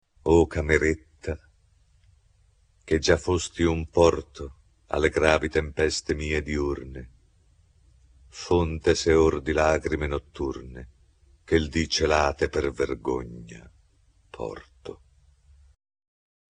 Per voi le più prestigiose interpretazioni del Maestro Arnoldo Foà